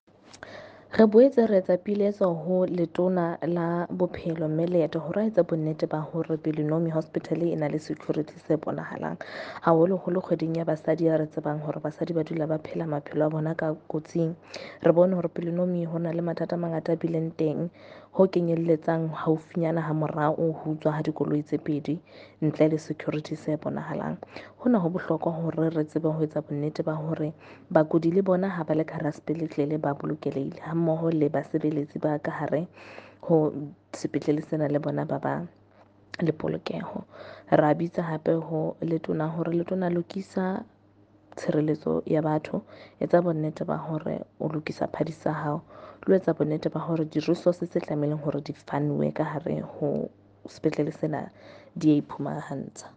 Afrikaans soundbites by Mariette Pittaway MPL and